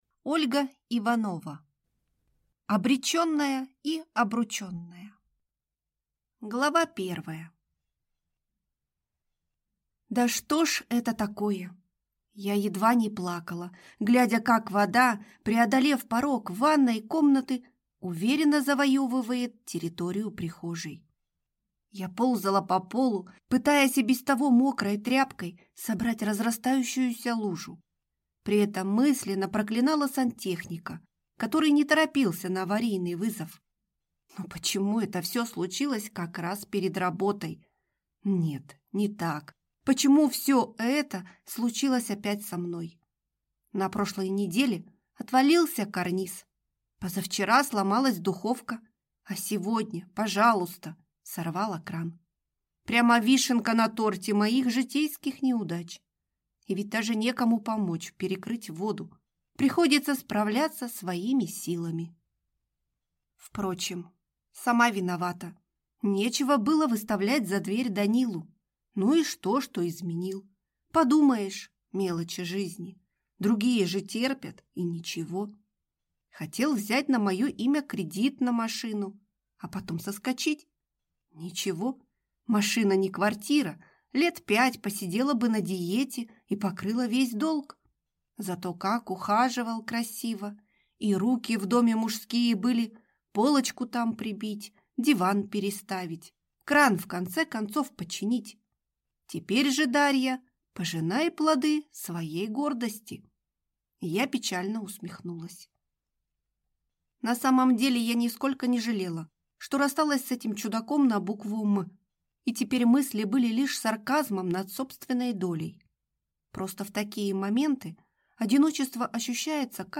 Аудиокнига Обреченная и обрученная | Библиотека аудиокниг